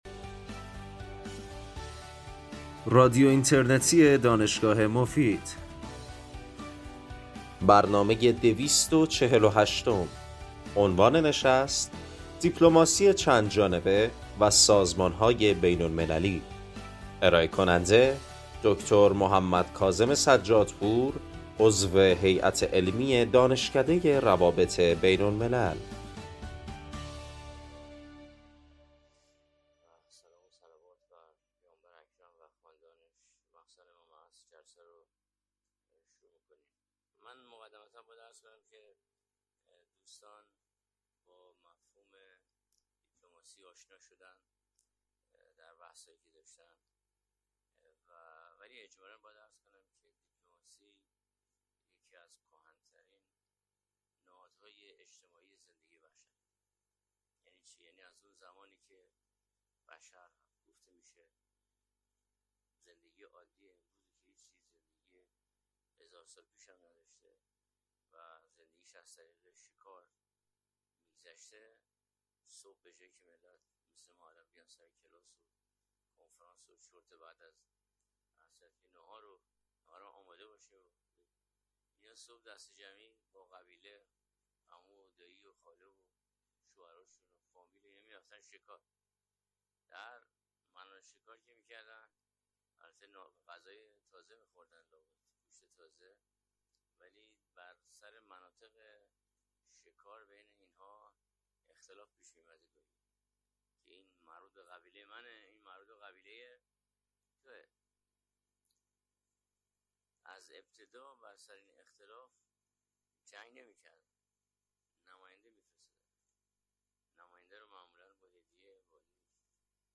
این سخنرانی در سال ۱۳۹۴ و در کارگاه آموزشی دیپلماسی و حقوق بشر ارائه شده است.
همچنین تاکید می‌کنند که بنیان سازمانهای بین المللی بر دیپلماسی چندجانبه استوار است و به شرح علل آن می‌پردازند. بخش پایانی برنامه به پرسش و پاسخ اختصاص دارد.